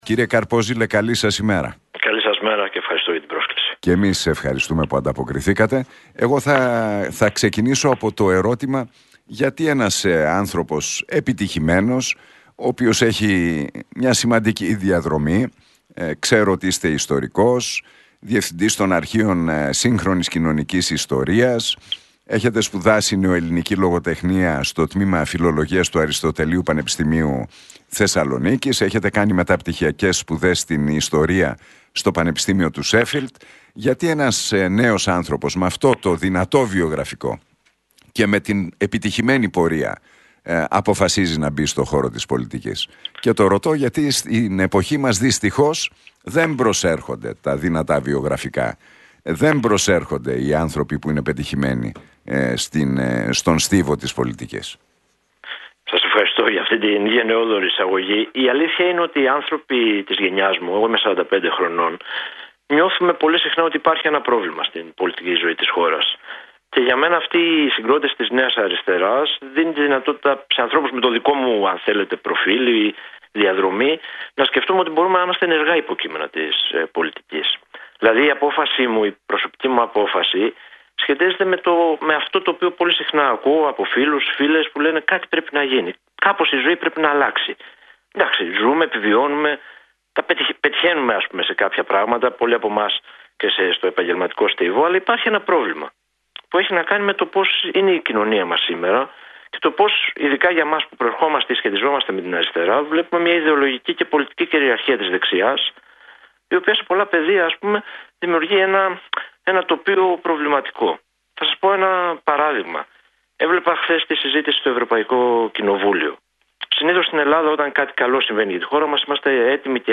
μιλώντας στον Realfm 97,8.